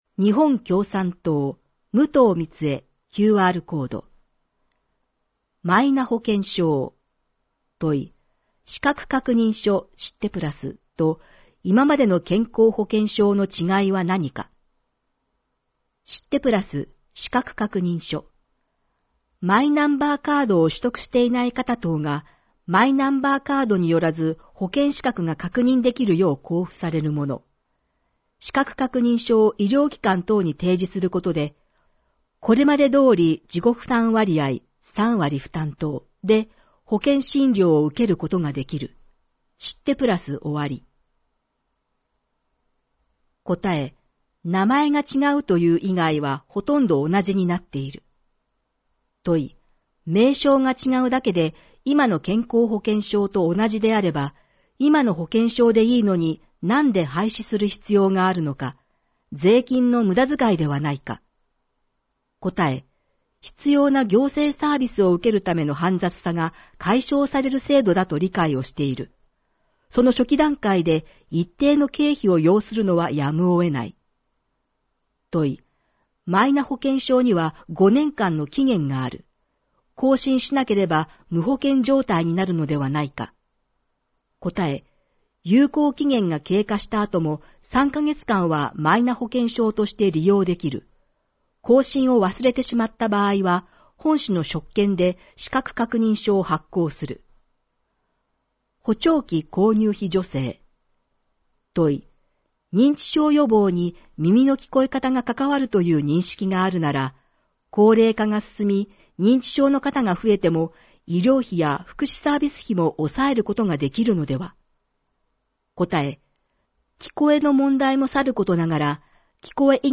• かしわ市議会だよりの内容を音声で収録した「かしわ市議会だより音訳版」を発行しています。
• 発行は、柏市朗読奉仕サークル（外部サイトへリンク）にご協力いただき、毎号行っています。